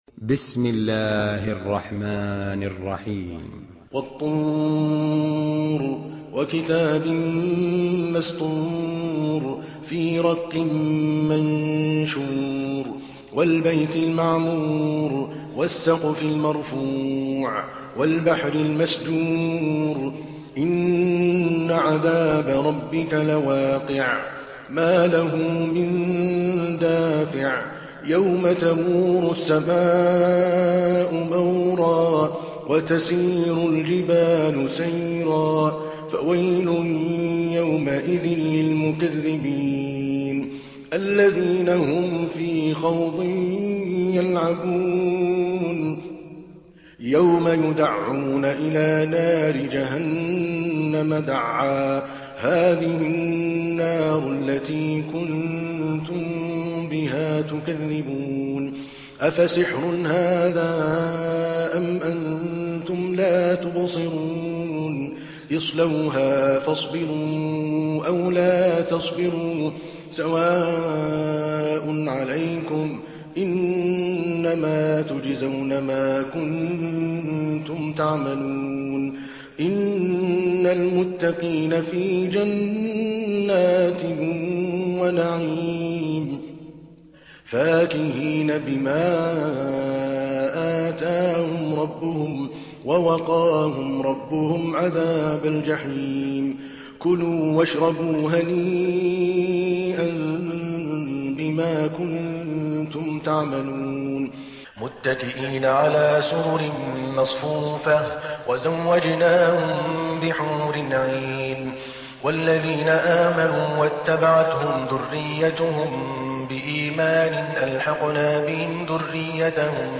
Surah Tur MP3 by Adel Al Kalbani in Hafs An Asim narration.
Murattal Hafs An Asim